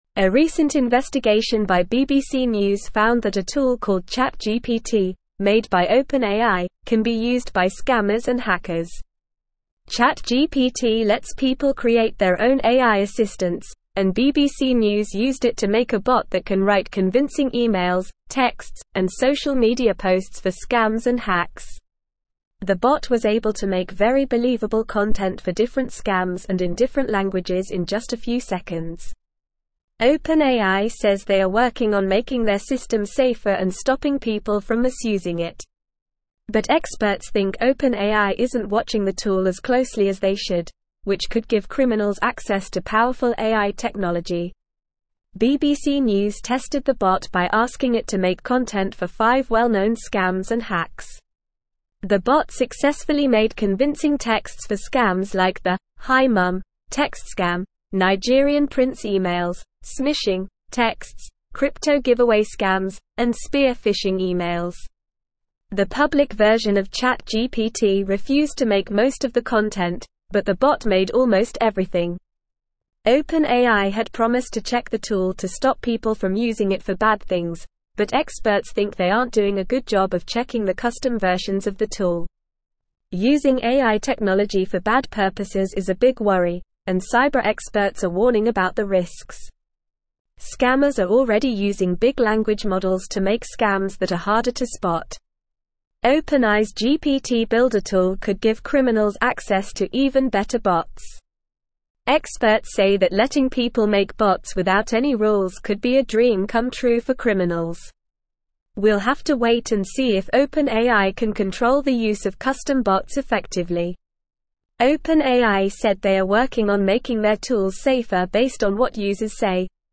Normal
English-Newsroom-Upper-Intermediate-NORMAL-Reading-OpenAIs-ChatGPT-Tool-Raises-Concerns-About-Cybercrime.mp3